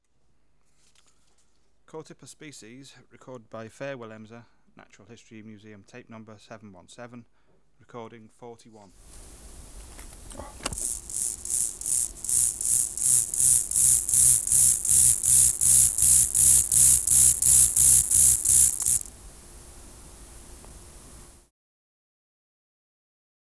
Natural History Museum Sound Archive Species: Chorthippus